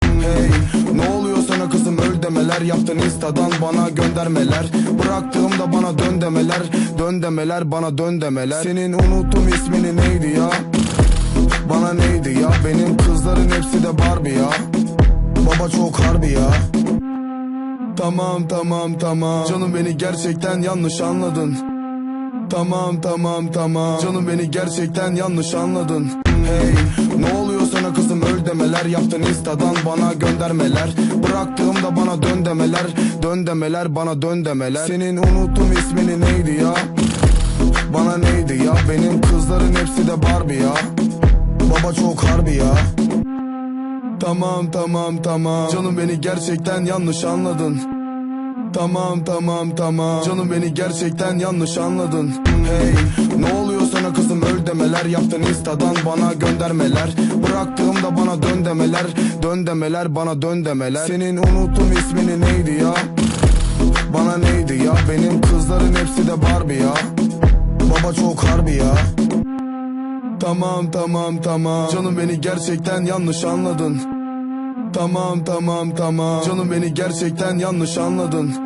دانلود آهنگ ترکی
Remix
با صدای مرد
با ریتم شاد